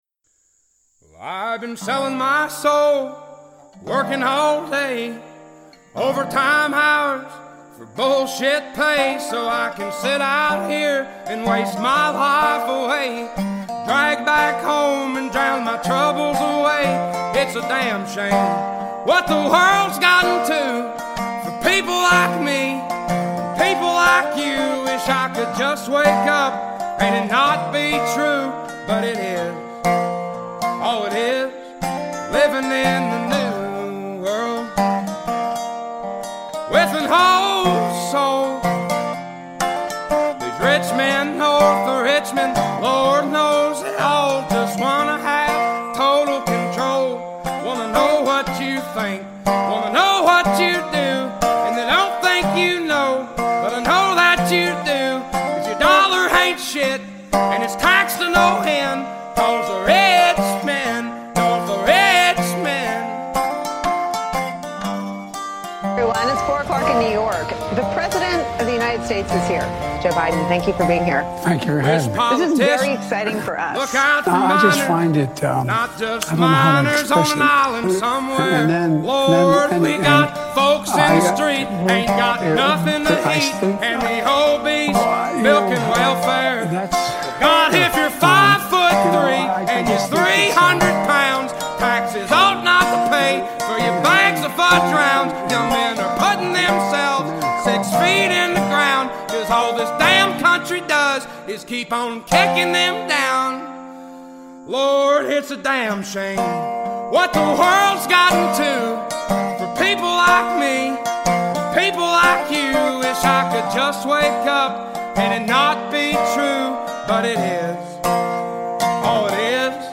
Ep 197 - Interview with Yuri Bezmenov former Soviet KGB agent - Exposing the ruling class playbook for 1 world System